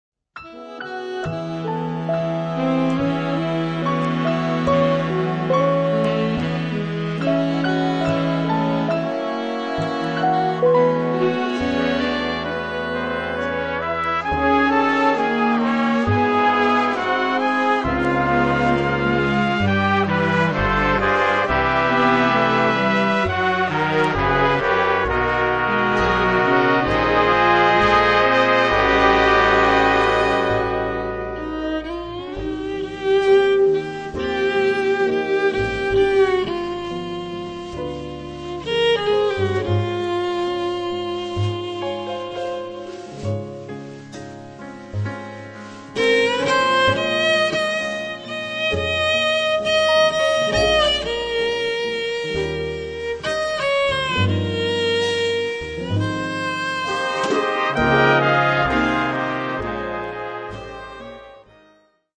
registrato dal vivo il 18 Luglio 2008